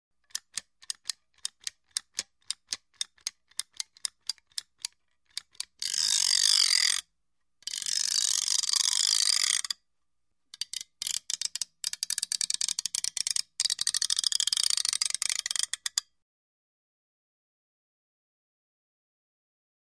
Caulking | Sneak On The Lot